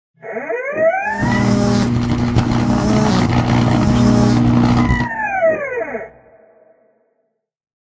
shock-short.ogg.mp3